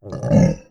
Monster_Hit1.wav